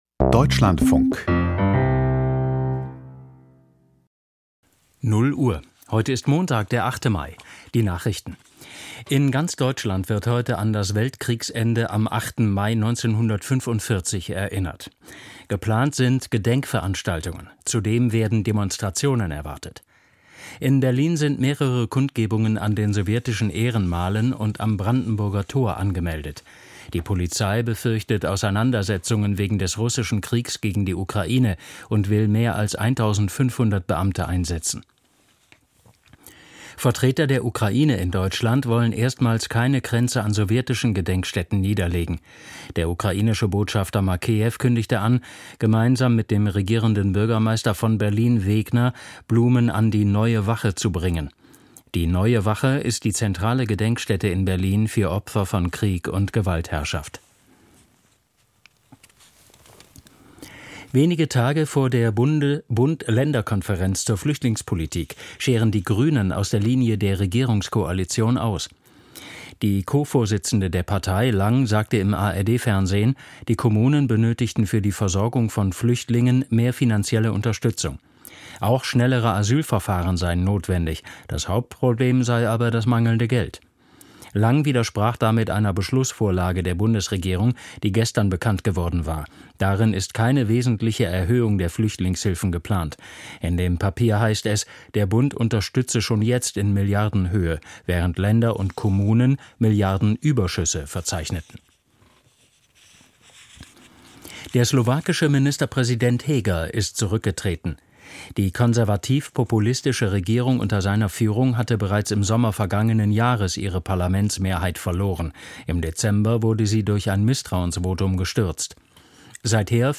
Nachrichten vom 08.05.2023, 00:00 Uhr